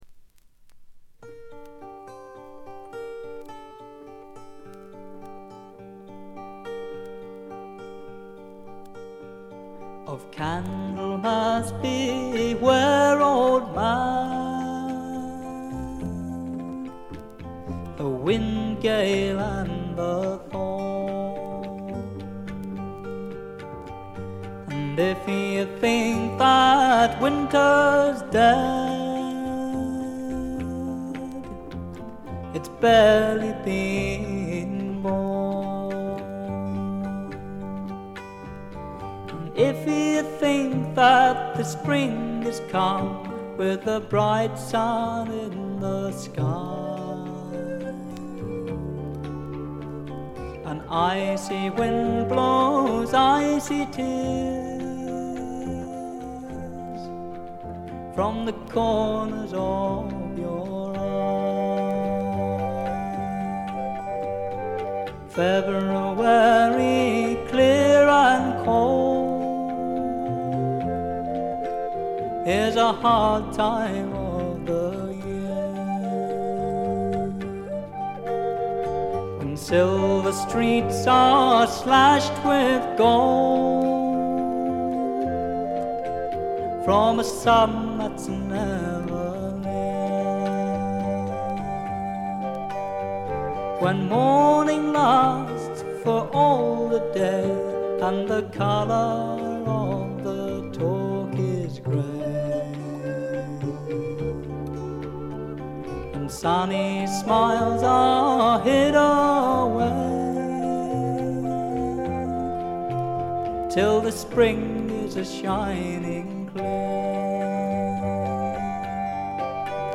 微細なチリプチ少々。
英国フォークロックの理想郷みたいな作品ですね。
試聴曲は現品からの取り込み音源です。
Tabla